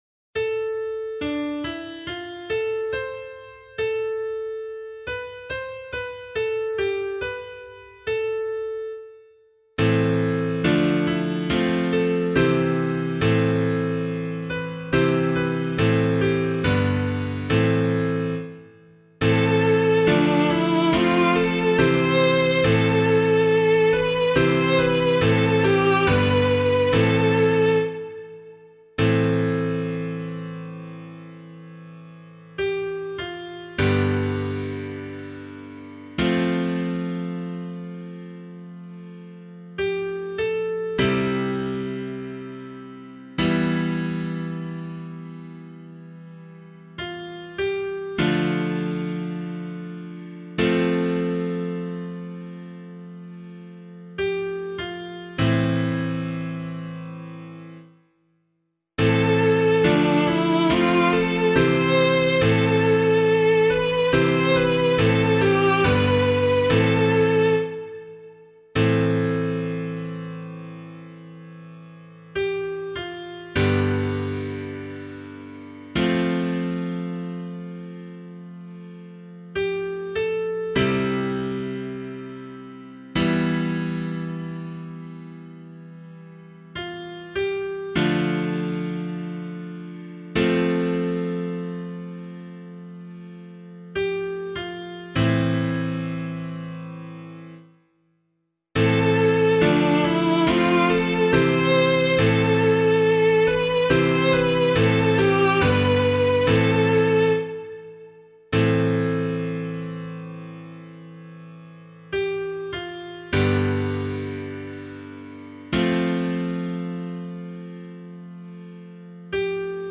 002 Advent 2 Psalm B [LiturgyShare 4 - Oz] - piano.mp3